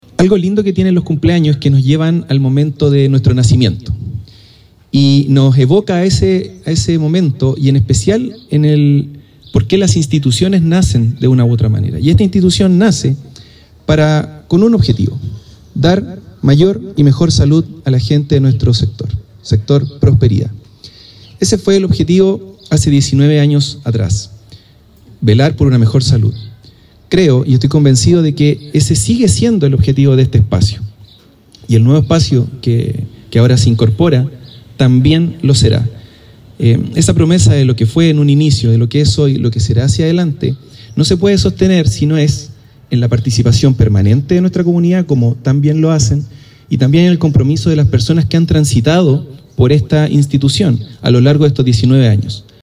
Con una emotiva ceremonia que reunió a autoridades, profesionales de la salud y vecinos del sector poniente de Curicó, el Centro Comunitario de Salud Familiar (Cecosf) Prosperidad conmemoró un nuevo aniversario, reafirmando su rol como referente en la atención primaria.